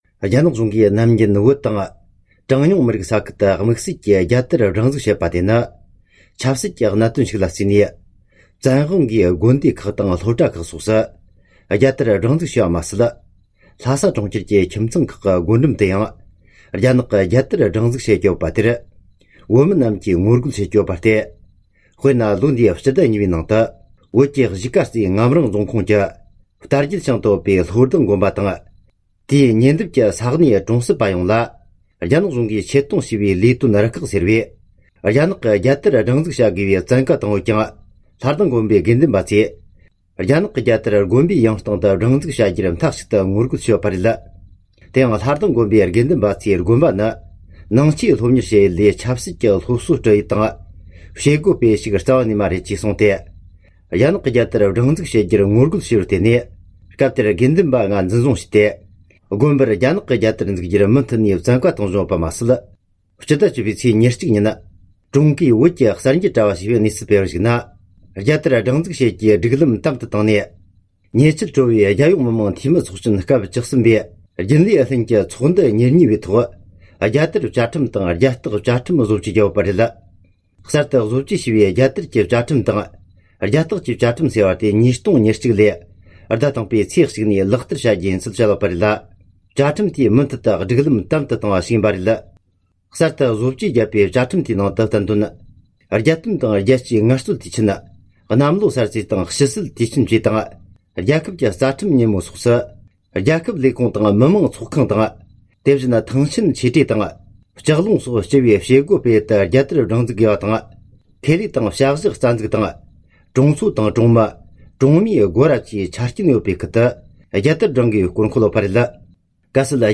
འབྲེལ་ཡོད་ཞིབ་འའཇུག་པར་བཅར་འདྲི་དང་གནས་ཚུལ་ཕྱོགས་བསྡུས་